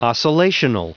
Prononciation du mot oscillational en anglais (fichier audio)
Prononciation du mot : oscillational